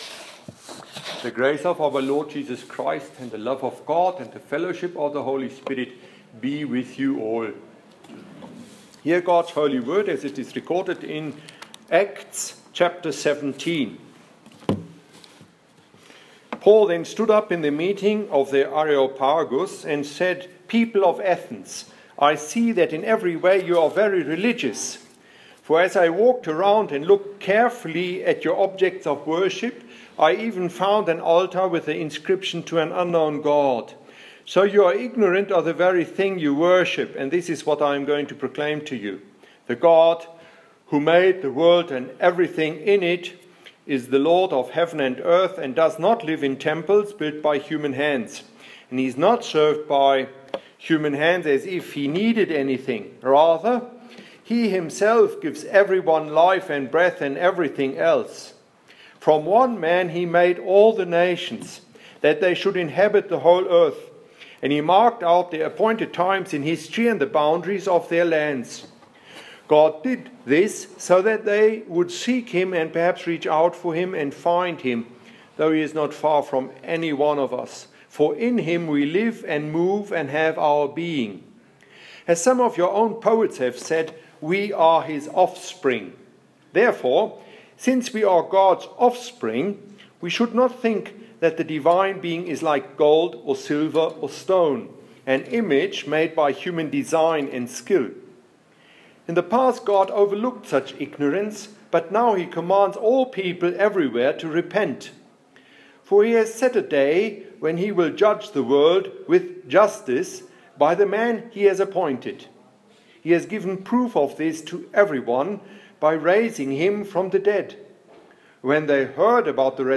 Sermon on Ac 17:22-32